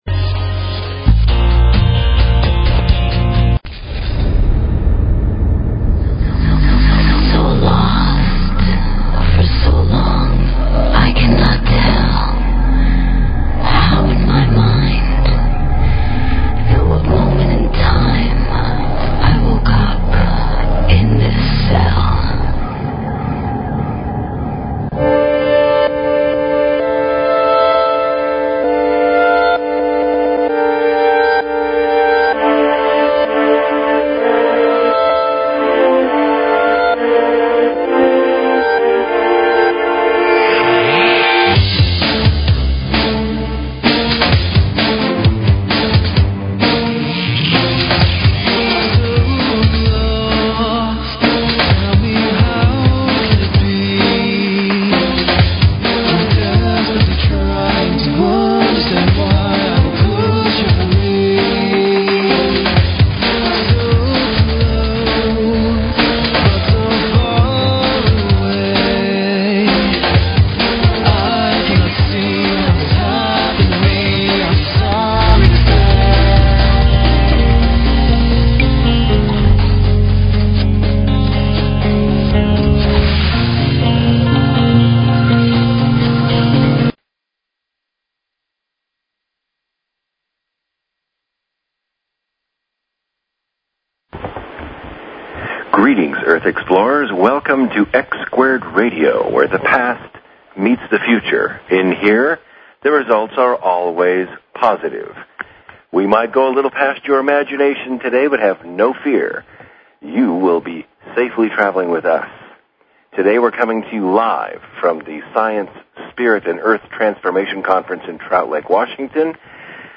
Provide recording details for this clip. Conference Date - The Science, Spirit, and Earth Transformation Conference at ECETI in Trout Lake, WA. We will broadcast live and have a guest of amazing report!